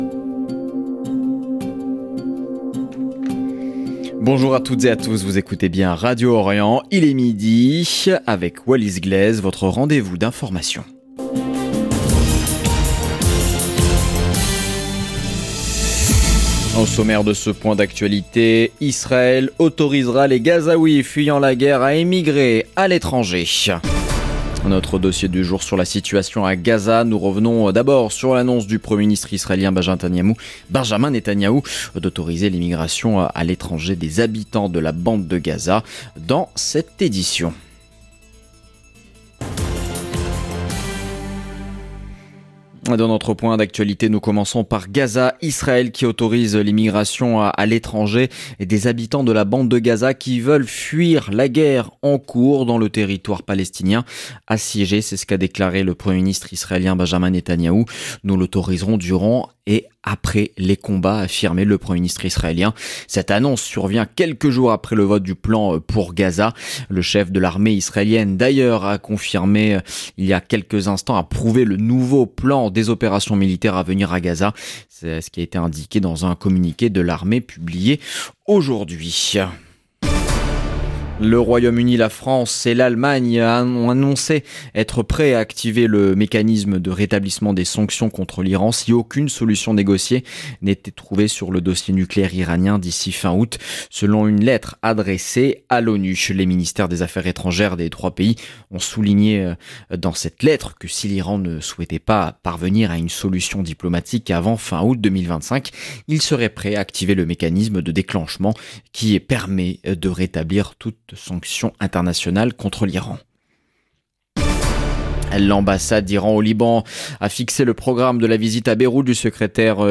Journal de midi du mercredi 13 août 2025